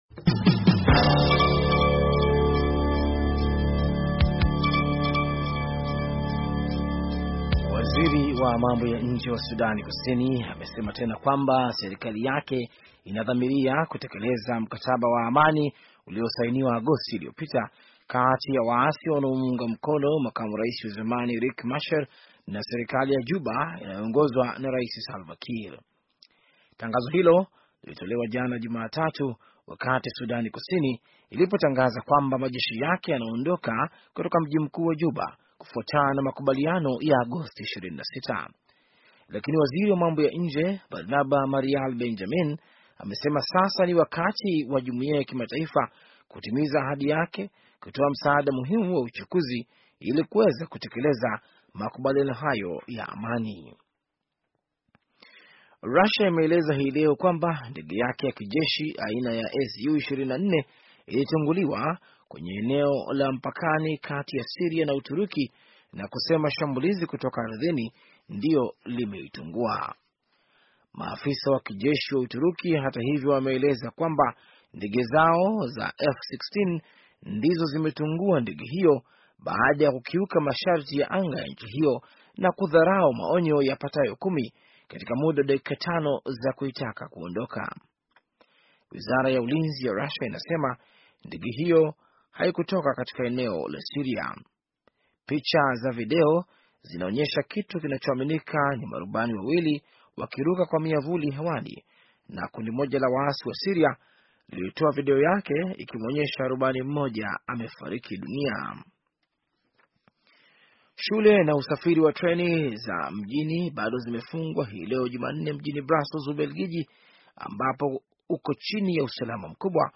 Taarifa ya habari - 5:40